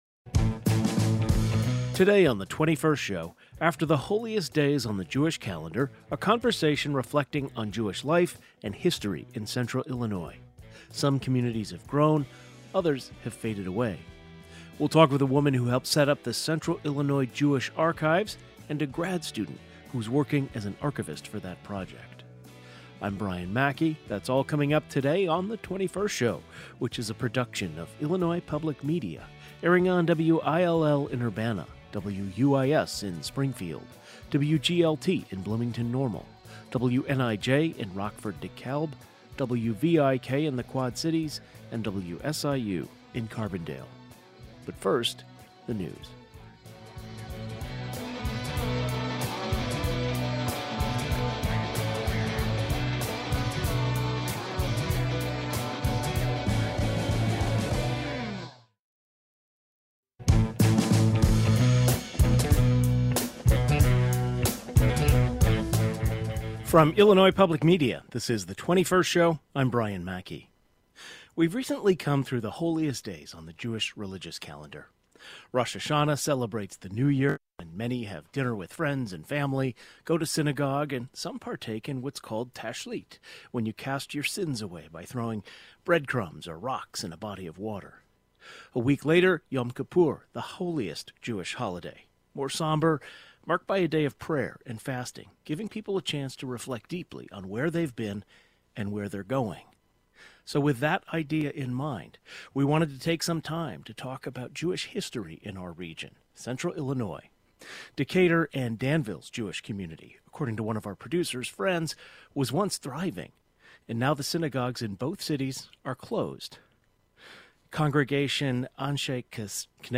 After the holiest days on the Jewish calendar, we held a conversation reflecting on Jewish life and history in central Illinois.